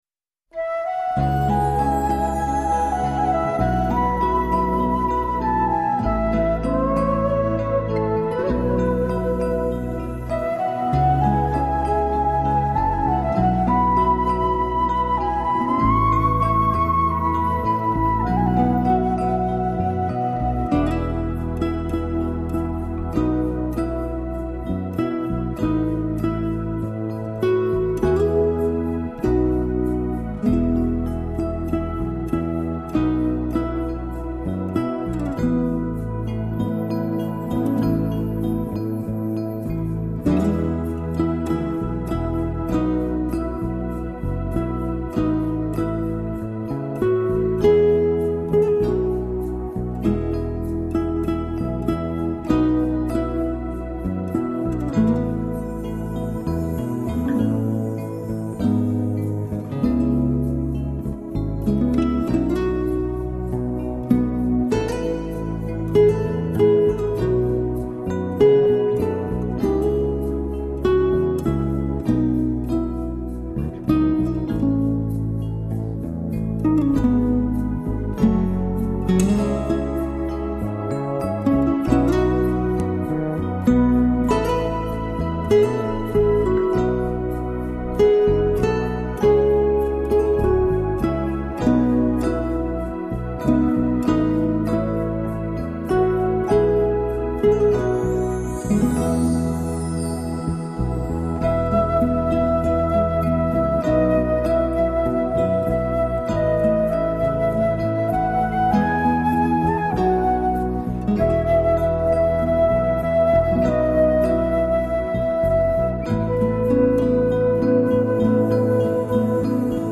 曲调优美、旋律通俗、雅俗共赏。音 乐中没有狂澜、没有惊涛，只有无限温柔、平缓抒情。